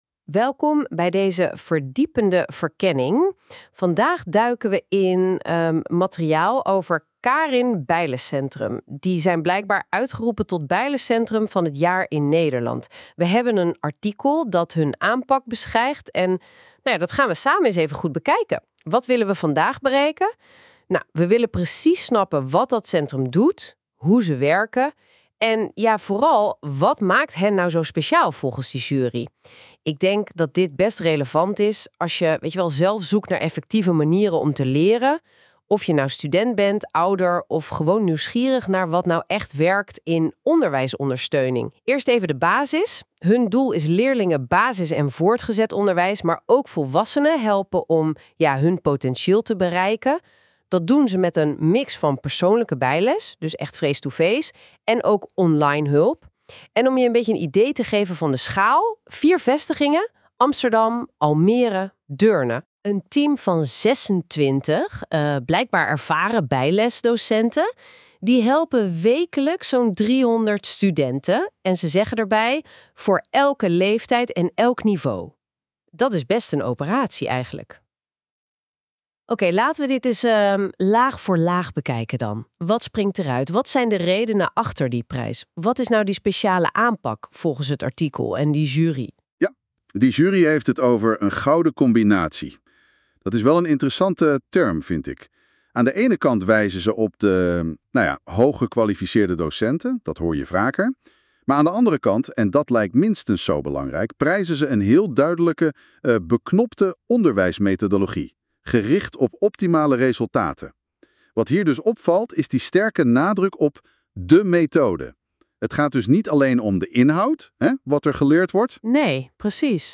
AI Podcast over Artikel Bijles Centrum van het jaar
AI-Podcast-over-Artikel-Beste-Bijles-Centrum-2025.mp3